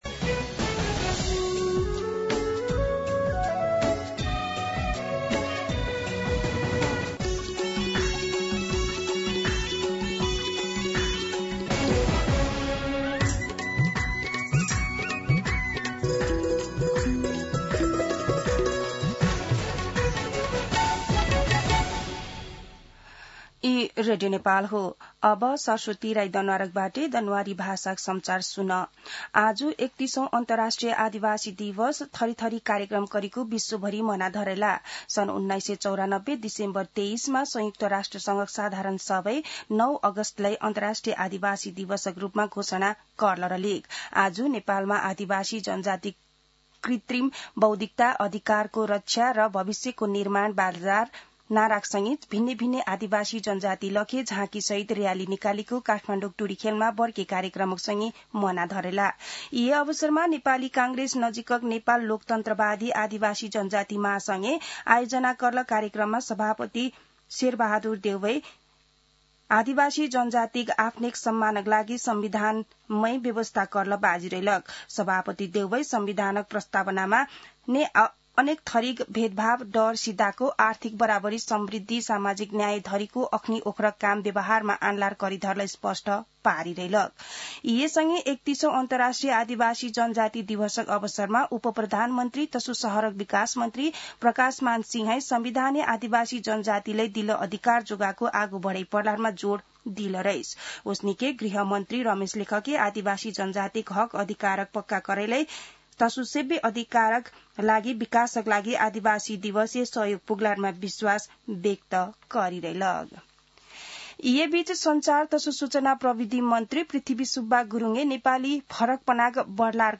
दनुवार भाषामा समाचार : २४ साउन , २०८२
Danuwar-News-24.mp3